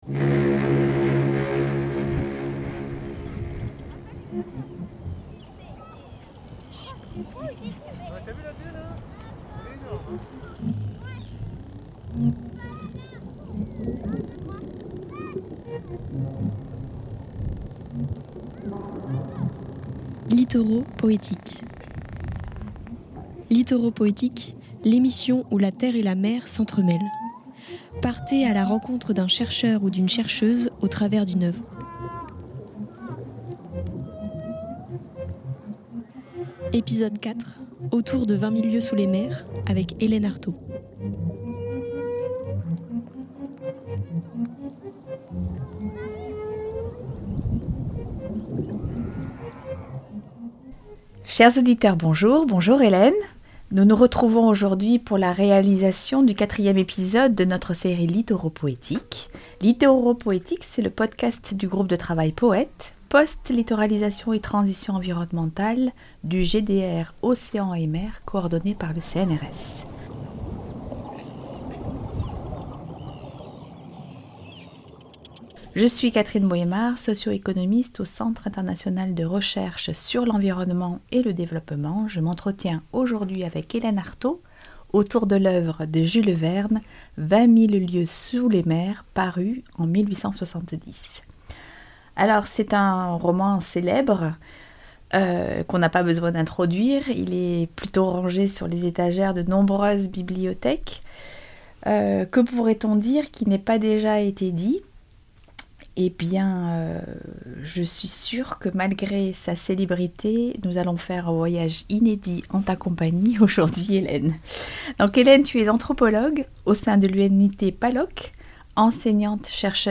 Littoraux poétiques est une émission qui entremêle la mer et la terre. Un chercheur ou une chercheuse raconte son parcours au travers d’une œuvre littéraire.
Interview